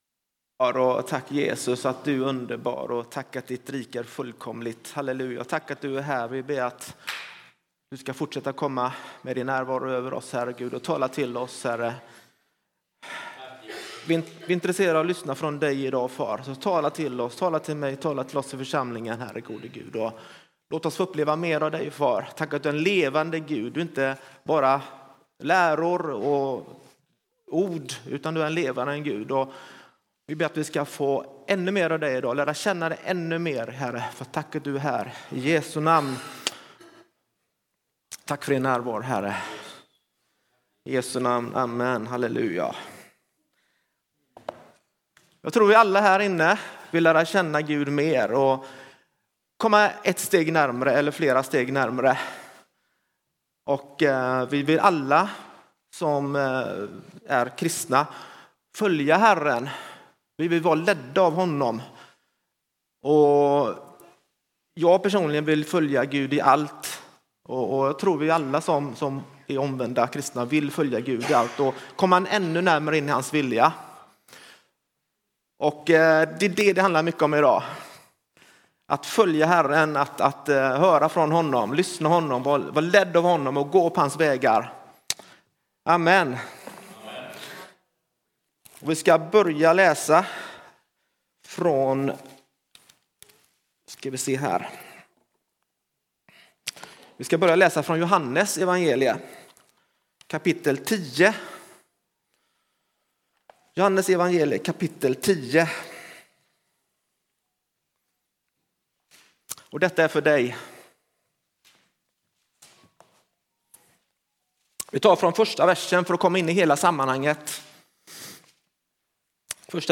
Pingst Vaggeryd söndag 18 januari 2026